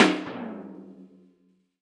Painted concrete.
Download this impulse response (right click and “save as”)